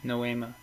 Ääntäminen
IPA : /nəʊˈiːmə/ IPA : /noʊˈimə/